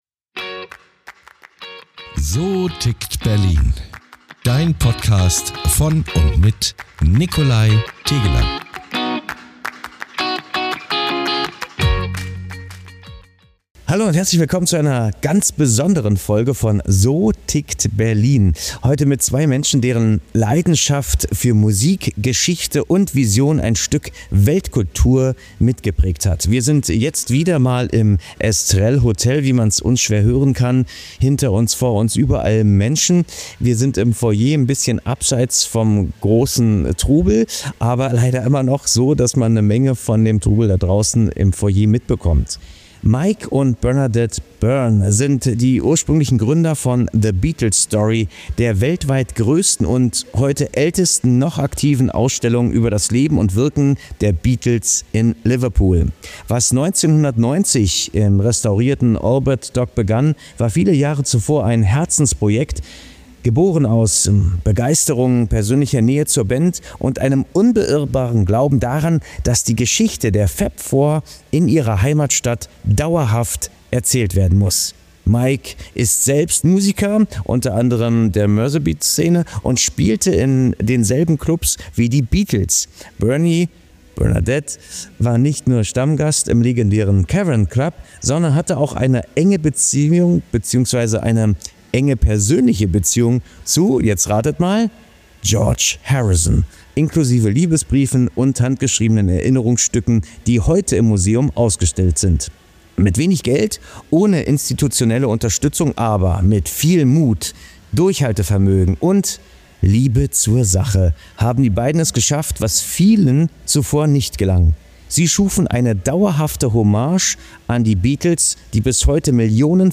Intro- / Outro-Sprecher